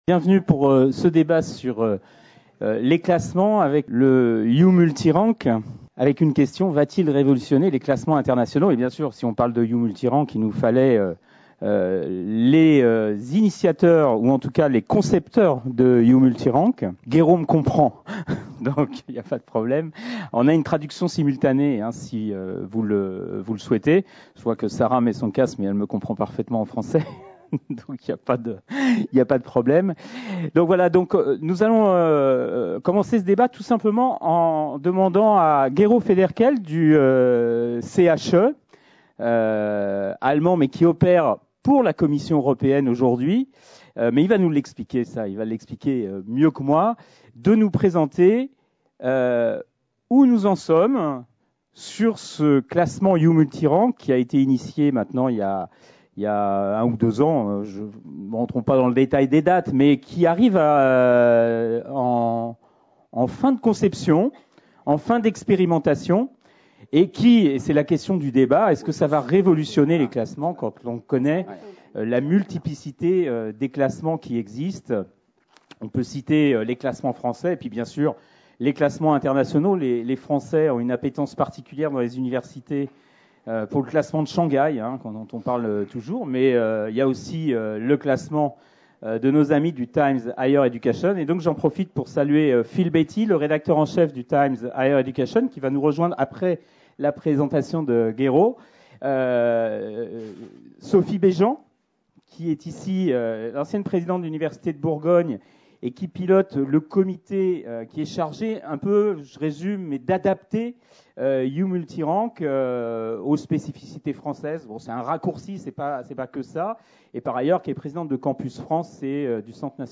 Rencontres Universités Entreprises (RUE 2014) : Le rendez-vous annuel des acteurs universitaires, économiques et institutionnels